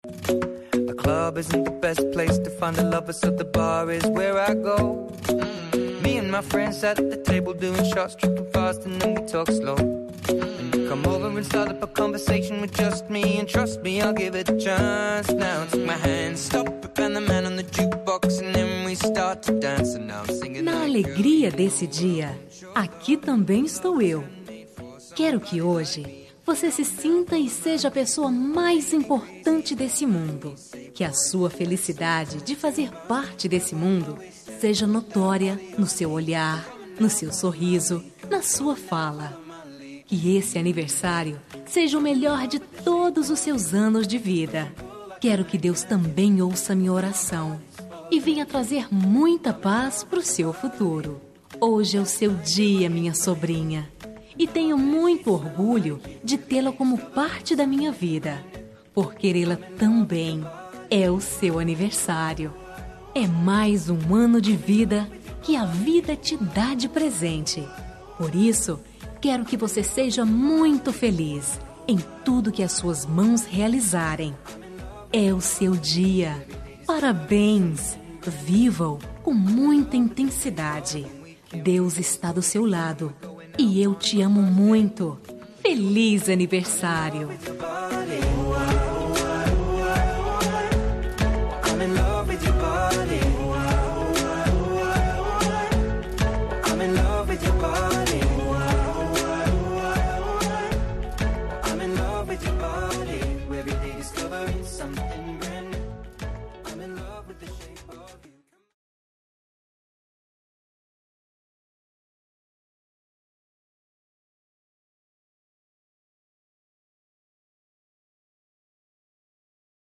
Aniversário de Sobrinha – Voz Feminina – Cód: 2169